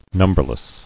[num·ber·less]